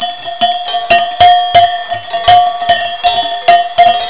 湖の近くはとても静かでした。
近くまで来た牛です。
カウベルの音がとってもいい雰囲気でした。